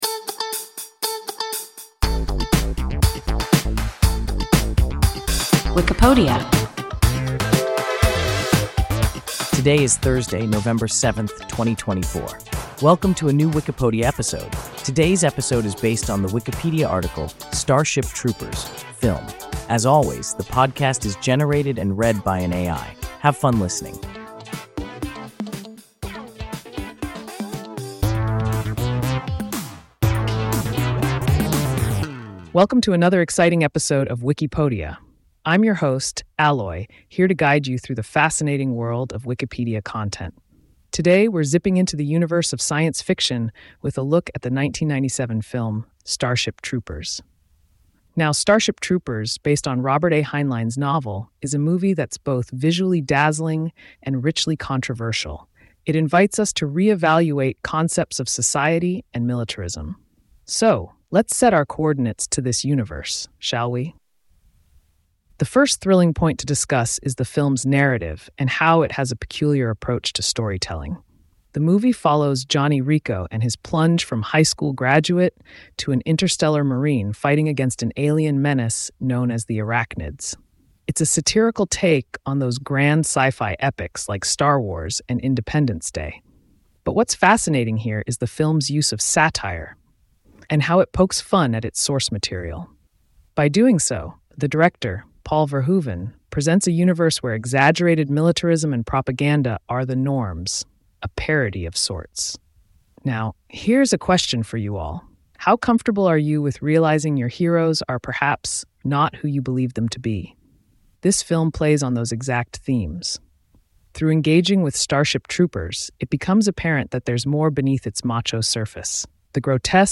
Starship Troopers (film) – WIKIPODIA – ein KI Podcast